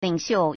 领袖 (領袖) lǐngxiù
ling3xiu4.mp3